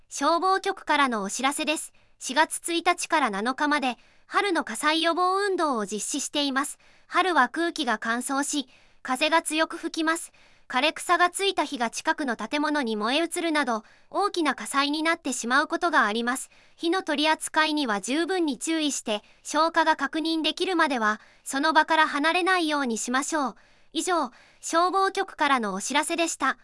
本音声データは、当運動期間中の館内放送のための音声データとなります。